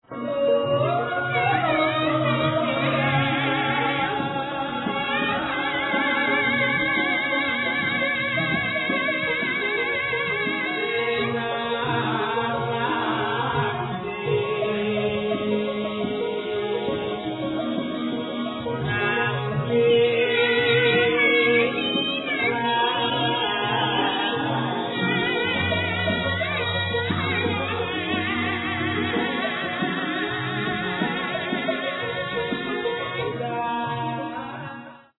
These selections were recorded in 1975 in Jogjakarta
slendro patbet Mamyara